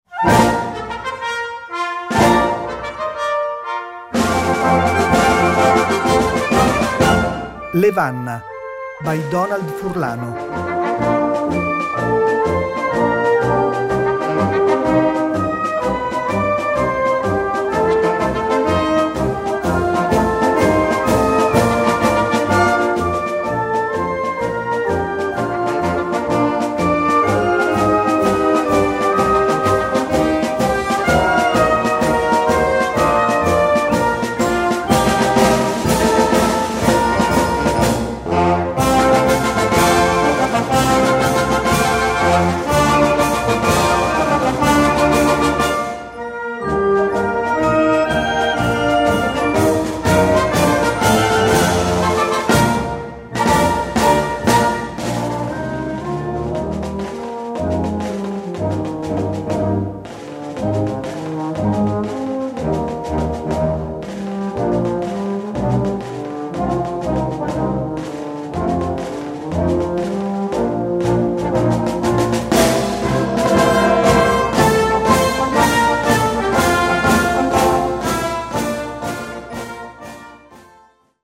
Gattung: Straßenmarsch
Besetzung: Blasorchester